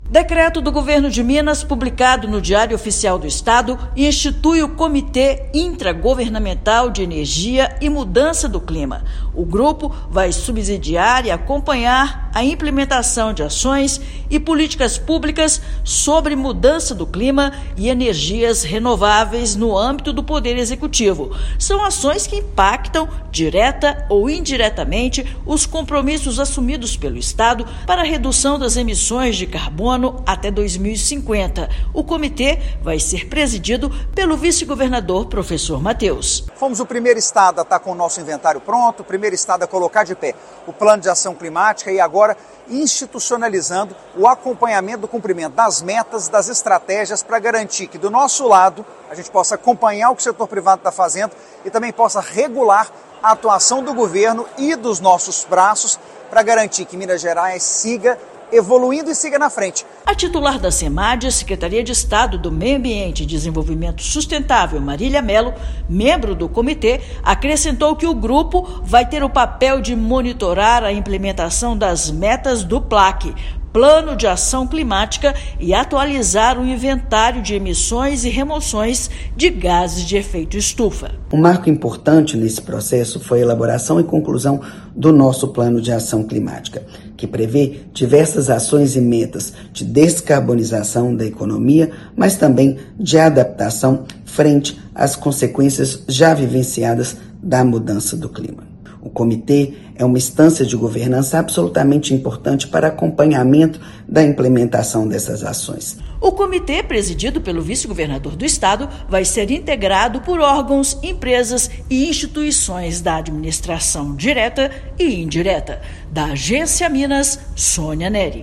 Grupo vai orientar elaboração de políticas públicas estaduais e monitorar ações para alcançar a neutralidade de emissões líquidas de carbono até 2050. Ouça matéria de rádio.